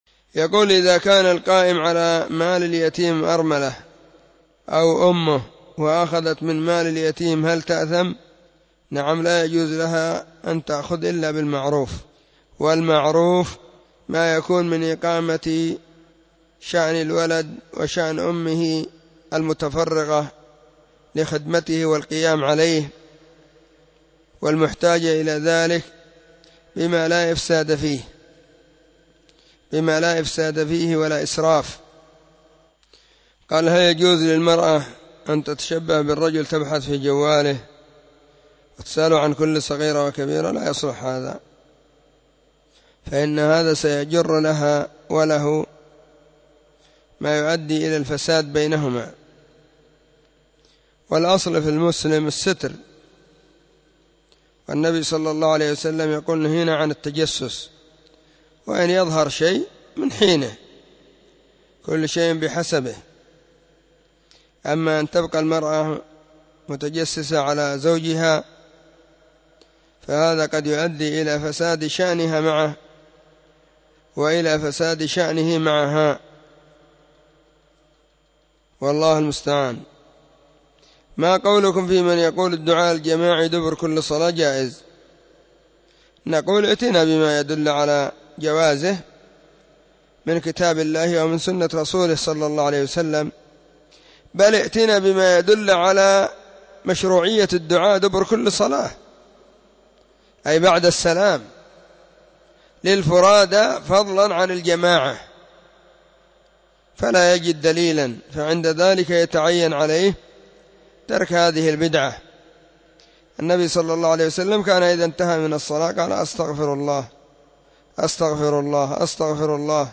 الأربعاء 24 محرم 1443 هــــ | فتاوى مجموعة | شارك بتعليقك